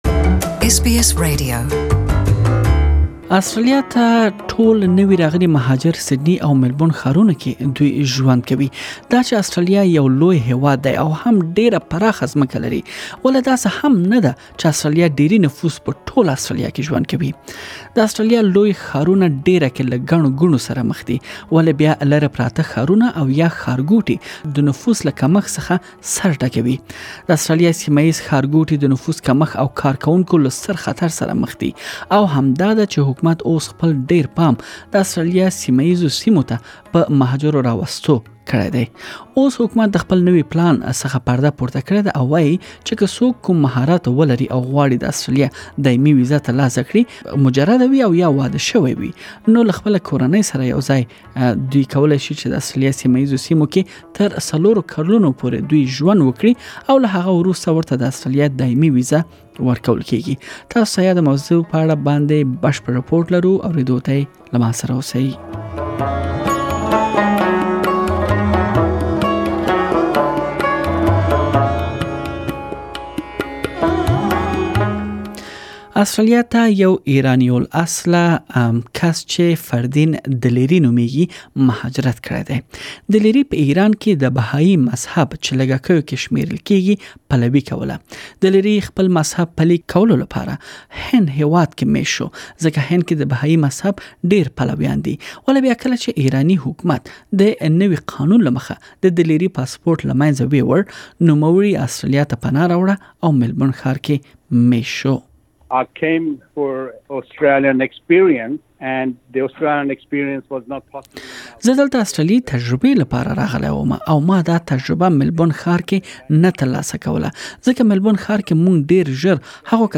Nearly all newly arrived migrants settled in Melbourne and Sydney last year while regional Australia is struggling to survive with a shrinking population. The Australian government is offering migrants new permanent residency pathways provided that they stay in regional areas for four years. Please listen to the full report in Pashto.